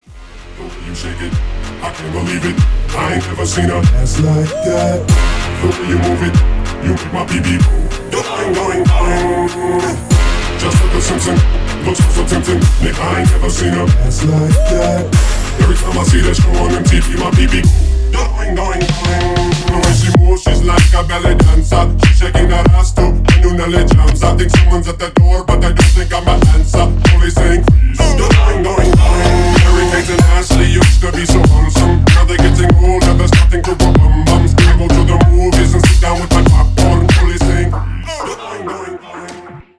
• Качество: 320, Stereo
качающие
клубная музыка
Стиль: G-house